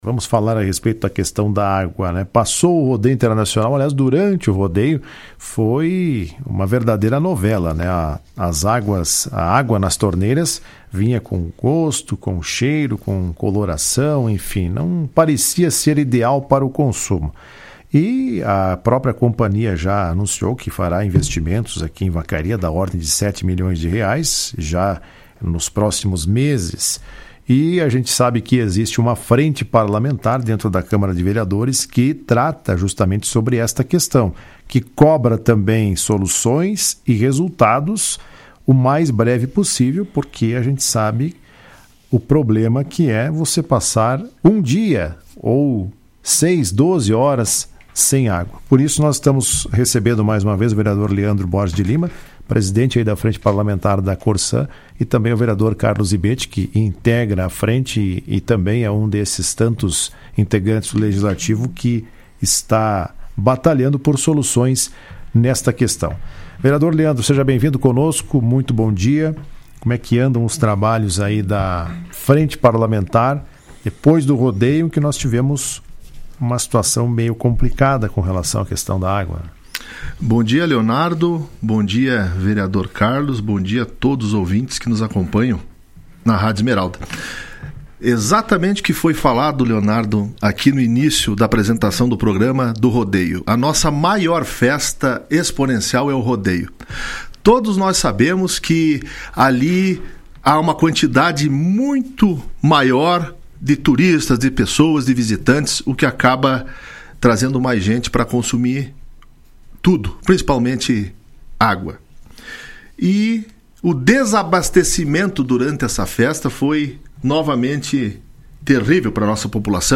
Em entrevista à Rádio Esmeralda, o presidente da Frente Parlamentar, vereador Leandro Borges de Lima, e o vereador Carlos Zibetti, destacaram que o trabalho da frente continua sendo executado de forma muito intensa, no sentido de fiscalizar de perto o contrato firmado entre a companhia e o município.